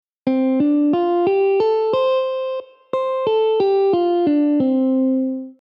Scottish Pentatonic Scale: Examples
(C-D-F-G-A)
Scottish-Pentatonic-AUDIO.mp3